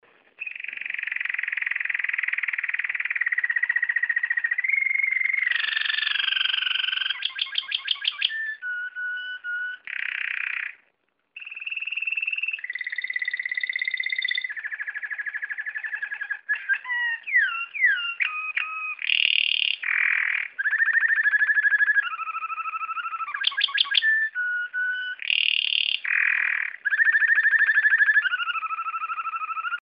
Звук канареечного пения